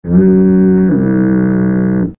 SZ_DD_foghorn.ogg